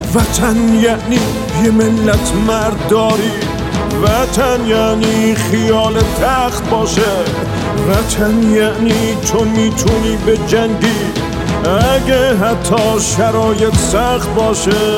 یه آهنگ شورانگیز در آستانه بازیهای تیم امید....پس فردا